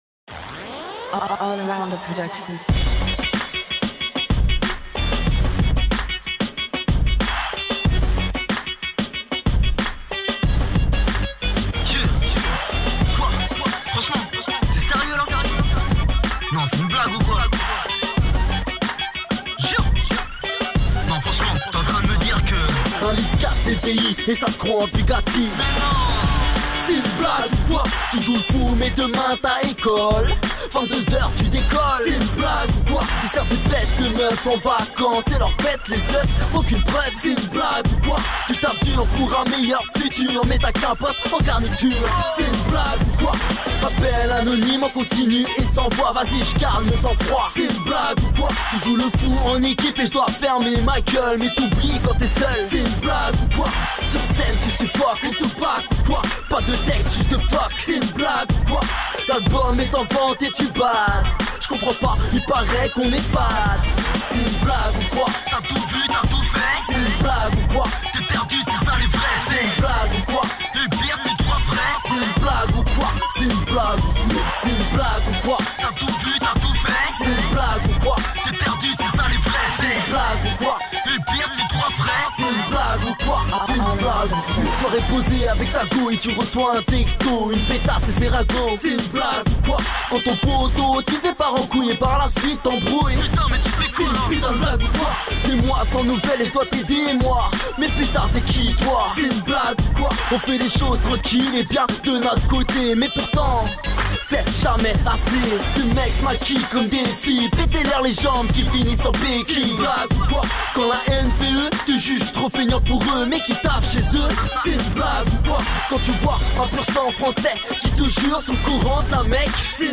Collectif Hip-Hop Lorientais.